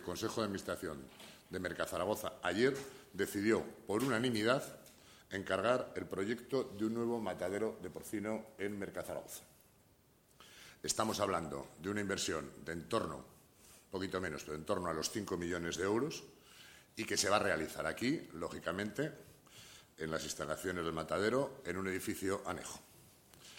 Carlos Pérez Anadón, Vicepresidente de Mercazaragoza